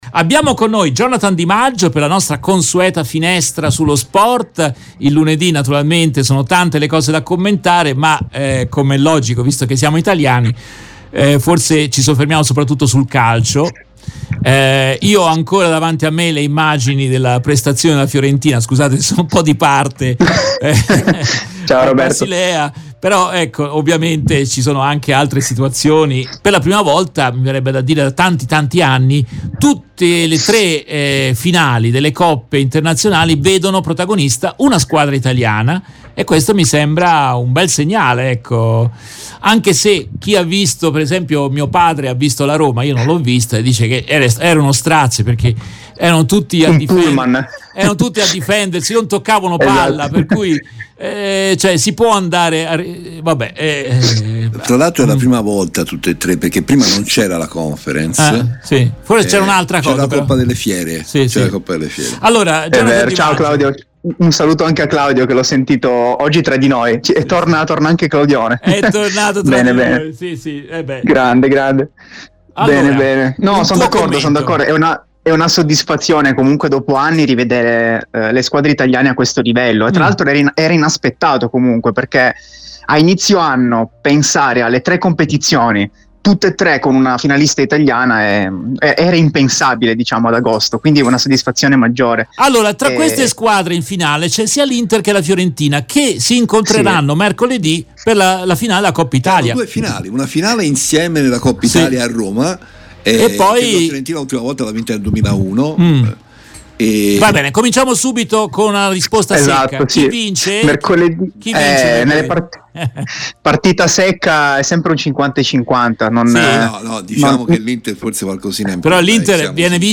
Nel corso della trasmissione in diretta del 22 maggio 2023